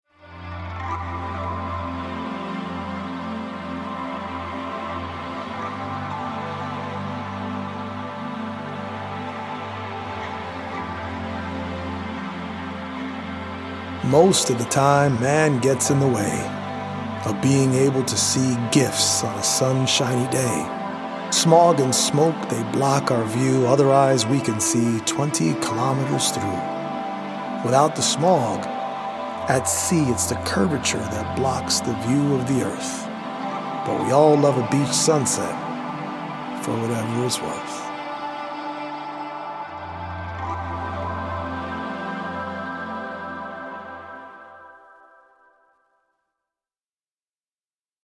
audio-visual poetic journey
as well as healing Solfeggio frequency music by EDM producer